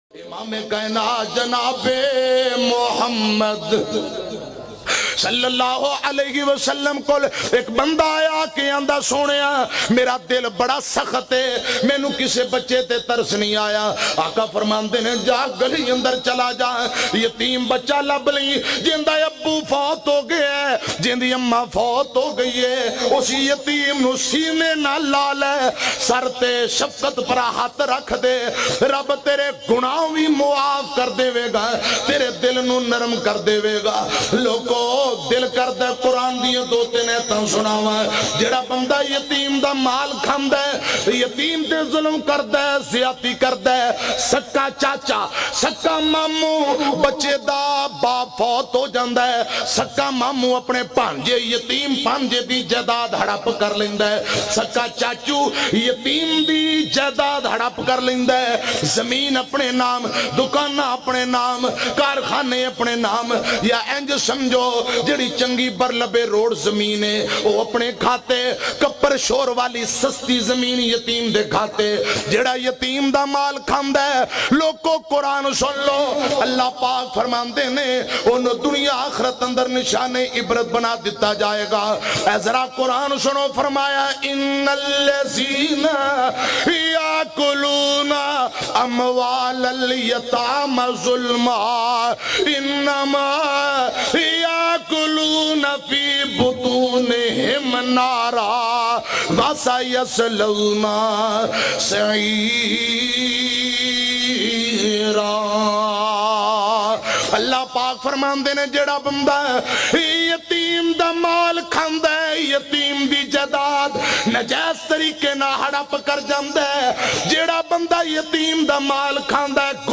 Jhanmiyon Ki Dastaan bayan mp3